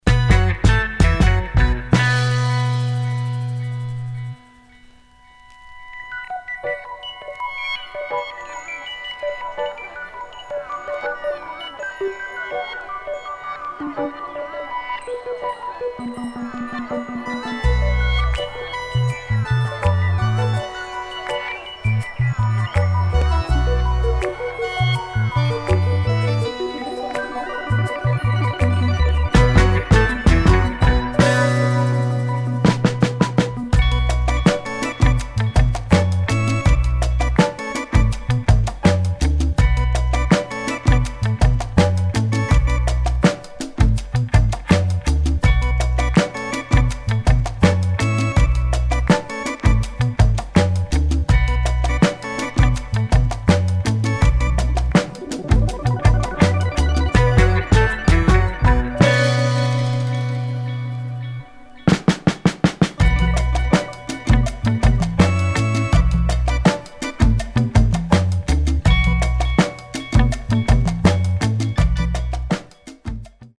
[ DOWNBEAT / AFRO / FUNK ]